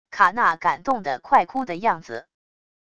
卡娜感动得快哭的样子wav音频